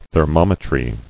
[ther·mom·e·try]